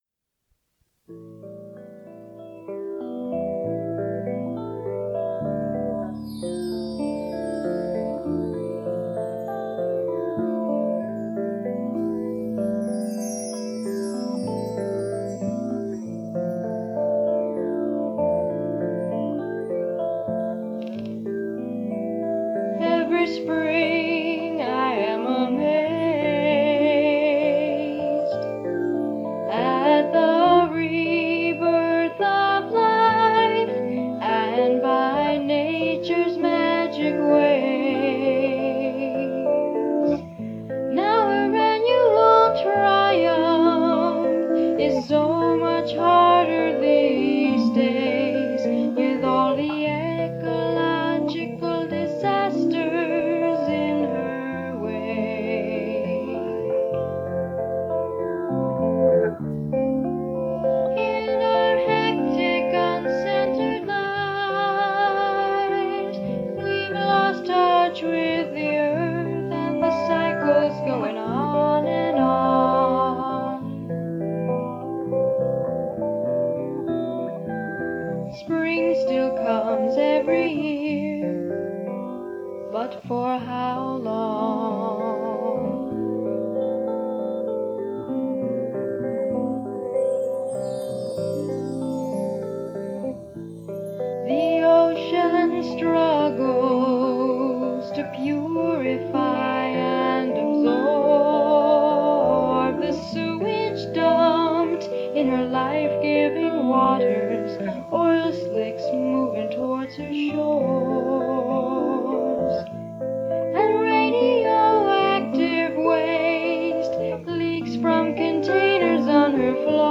singing and playing bass
keyboard
and often singing harmony or sharing the lead vocal.
Unfortunately, some of the recordings are not the greatest in terms of quality